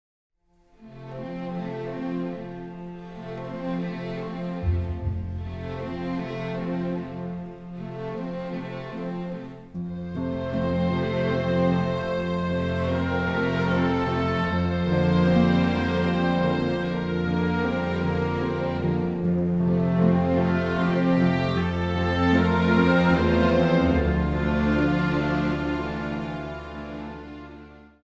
The score build upon a richly symphonic tapestry
orchestral tension for moments of great distress